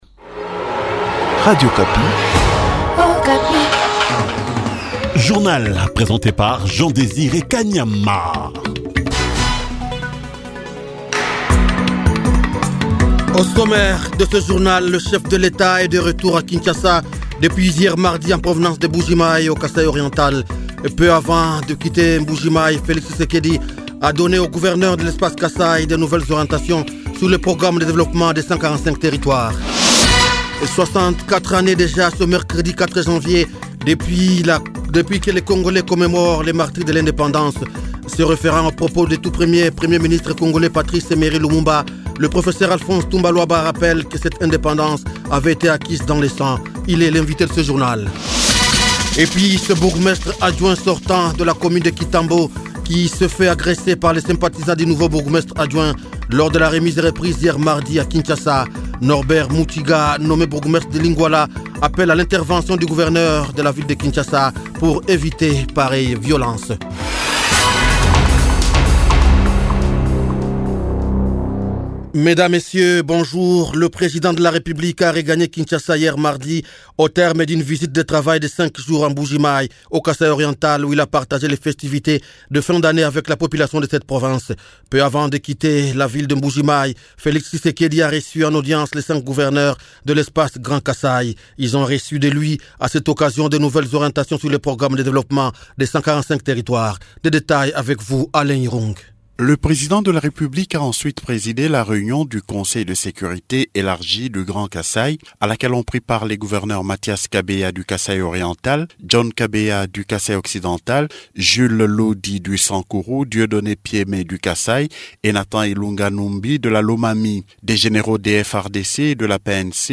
Journal Francais